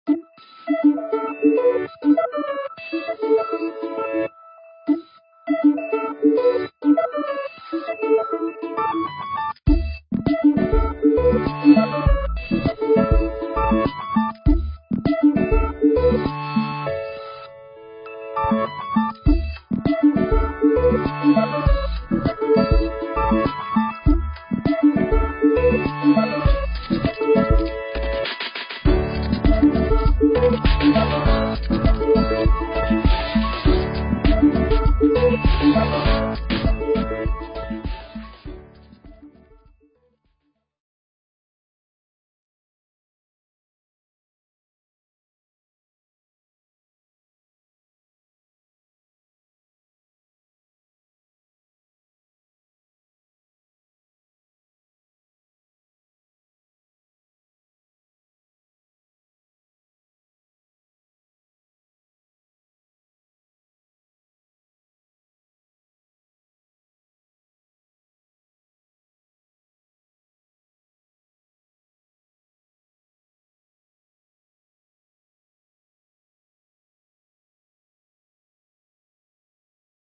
1342_Hustle_and_bustle.mp3